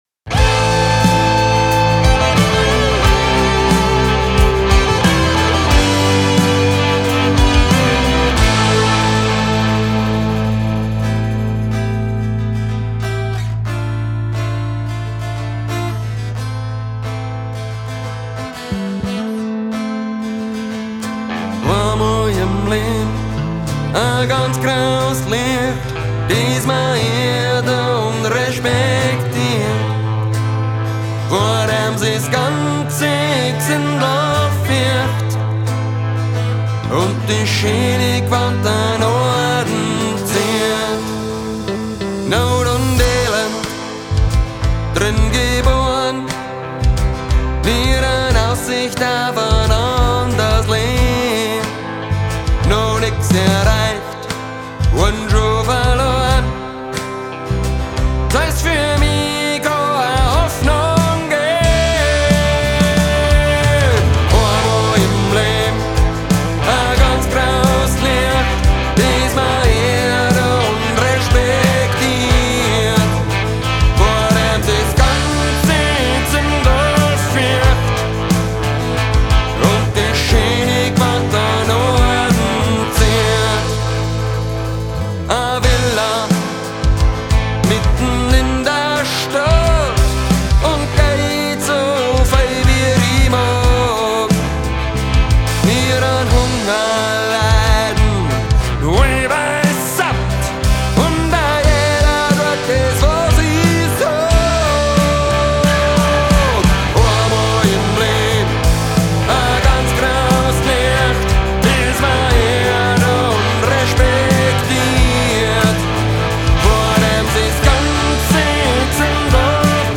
So, hab mich auch mal an diesem tollen Teil versucht: Wollte eher einen transparenten (und vor allem im Refrain doch kraftvollen) Mix und habe deshalb die flanger/chorus git. im hinteren Teil sehr, sehr sparsam verwendet.